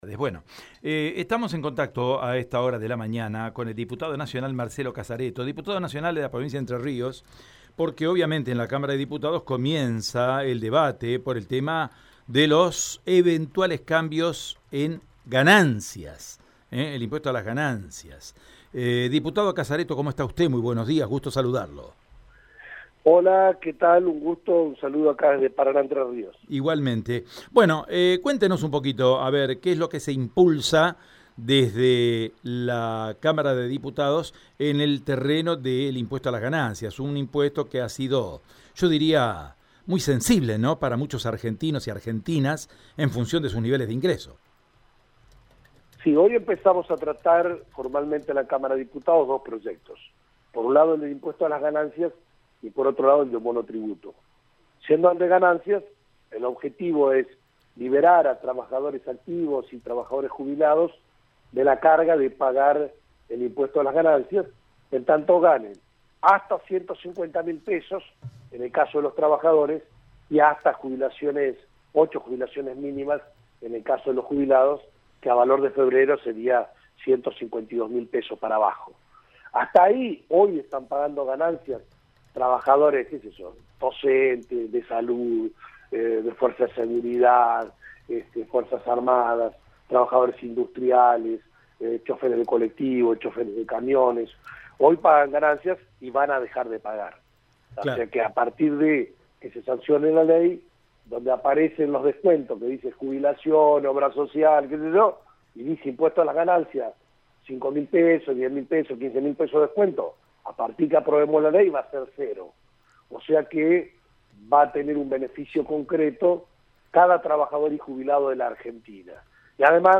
En diálogo con Radio EME, el diputado nacional por Entre Ríos, Marcelo Casaretto, explicó que “hoy empezamos a tratar dos proyectos, por un lado el de impuestos a las ganancias y por el otro de monotributo”.
Diputado-Nacional-e-Integrante-de-la-Comisión-de-Presupuesto-de-DiputadosAR.mp3